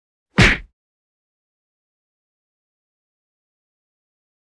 赤手空拳击中肉体2-YS070524.wav
WAV · 769 KB · 立體聲 (2ch)
通用动作/01人物/03武术动作类/空拳打斗/赤手空拳击中肉体2-YS070524.wav